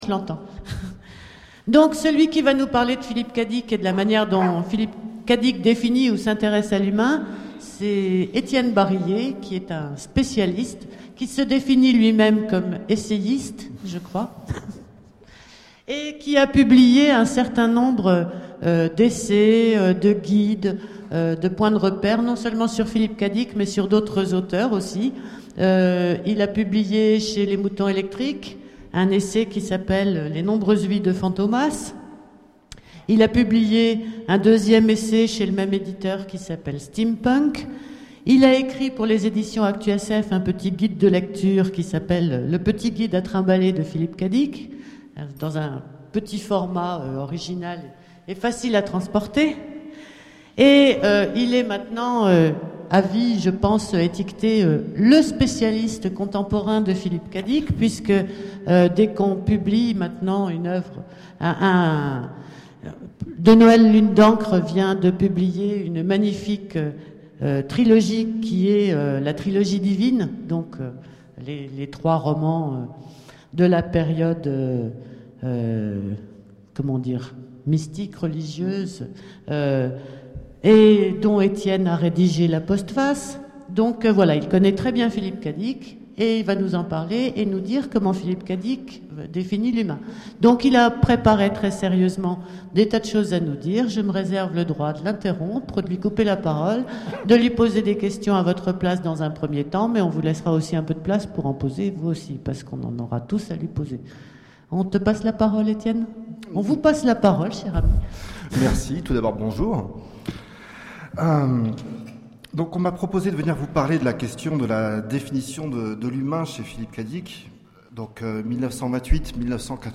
Imaginales 2013 : Conférence Définir l'humain